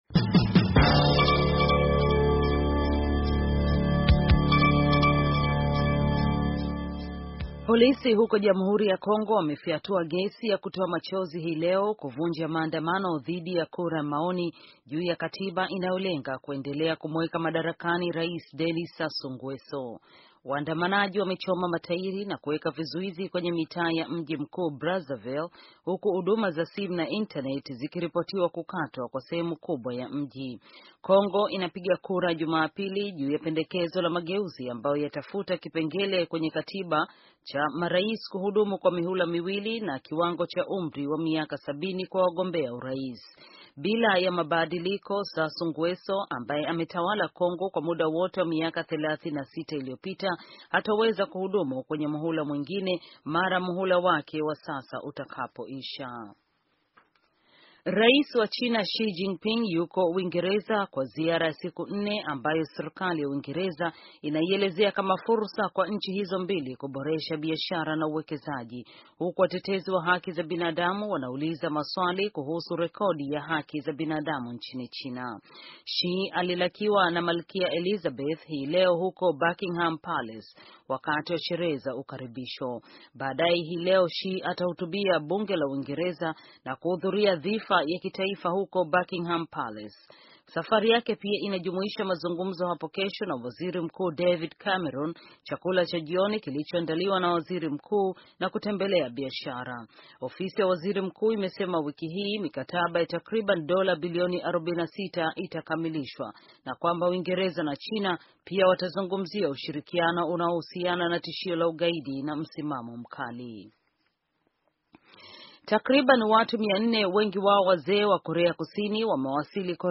Taarifa ya habari - 5:10